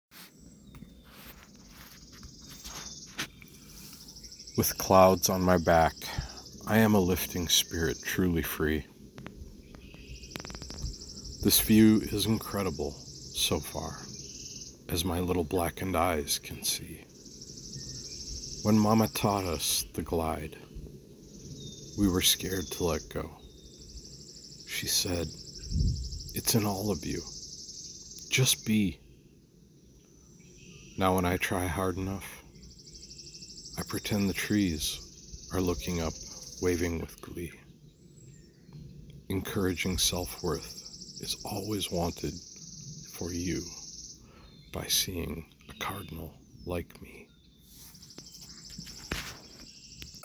What a sensyal voice you have, In addition, a charming poem.